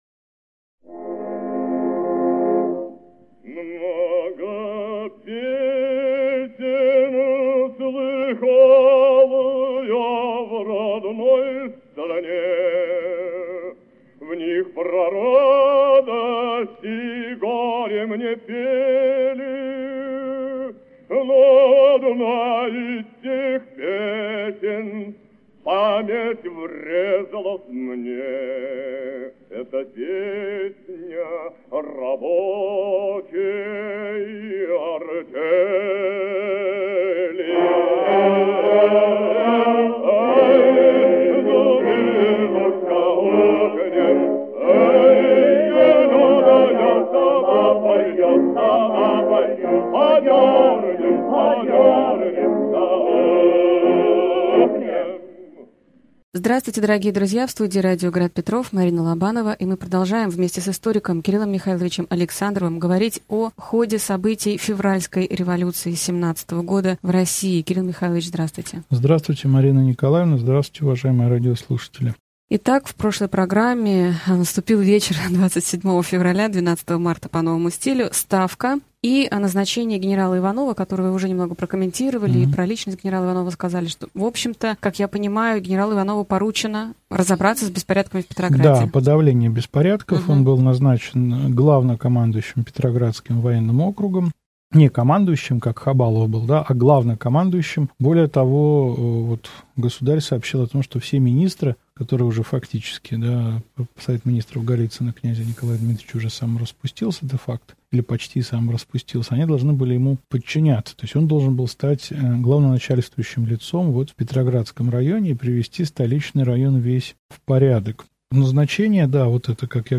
Аудиокнига Февральская революция и отречение Николая II. Лекция 16 | Библиотека аудиокниг